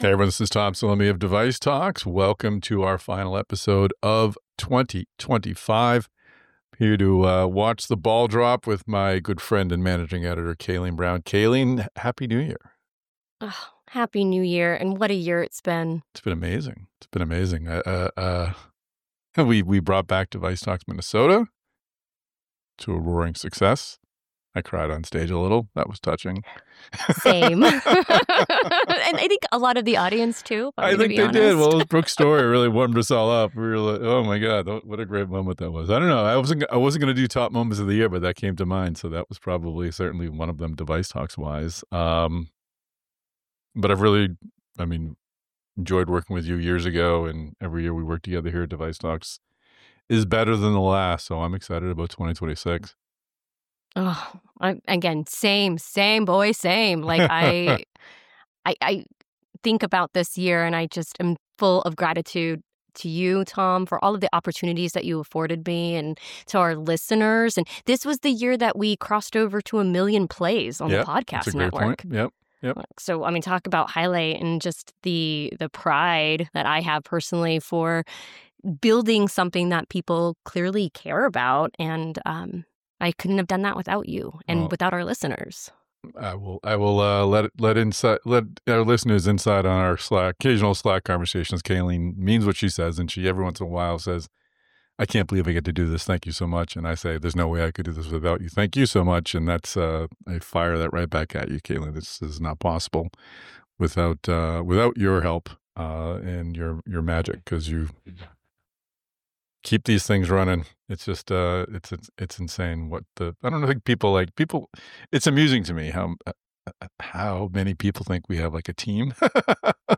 In this keynote address from DeviceTalks West